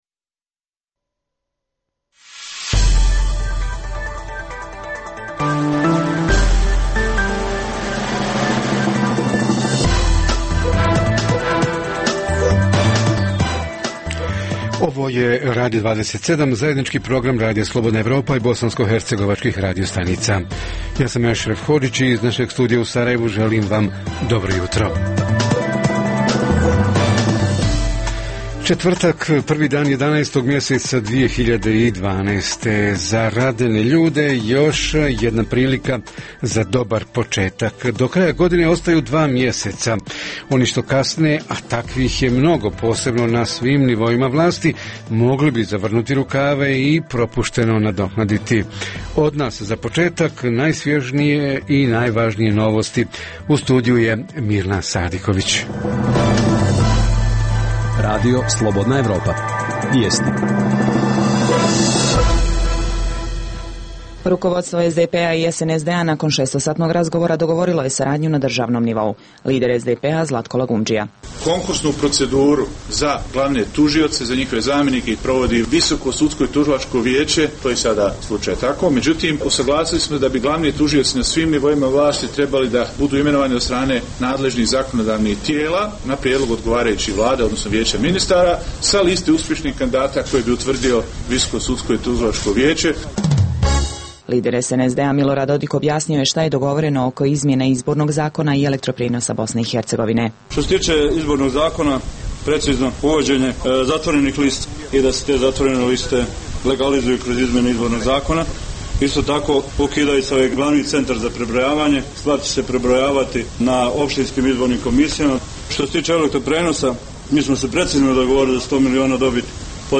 - Središnja tema jutra: budžeti lokalnih zajednica za 2013. godinu – šta iz budžeta traže građani, kako obrazlažu svoje zahtjeve i kako lobiraju da njihovi zahtjevi budu i ostvareni? O tome će – sa svojim sagovornicima - naši reporteri iz Travnika, Doboja i Banje Luke.